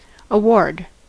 Ääntäminen
Ääntäminen US : IPA : [əˈwɔːd]